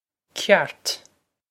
ceart cyart
This is an approximate phonetic pronunciation of the phrase.